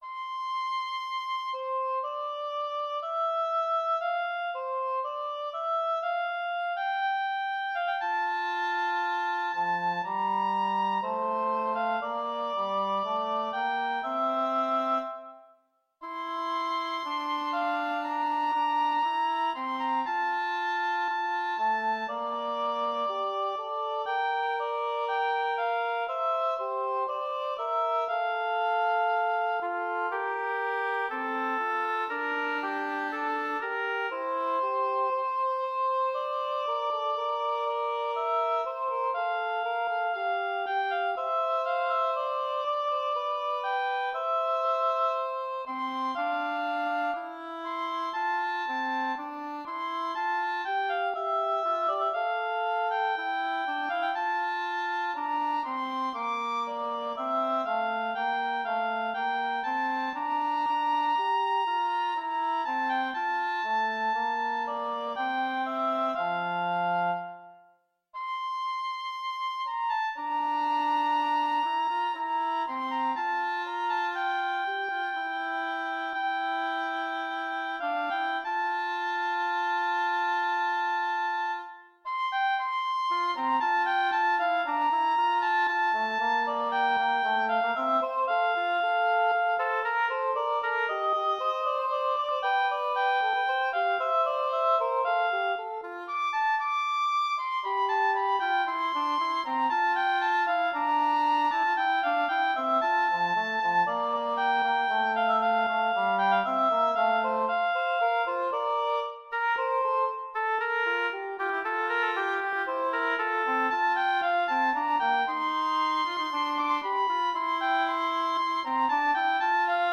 Intermediate oboe & english horn duet